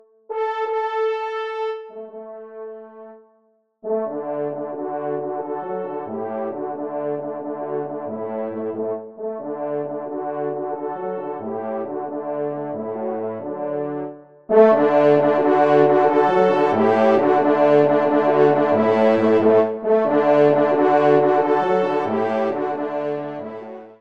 Genre :  Divertissement pour Trompes ou Cors
Pupitre 4°  Cor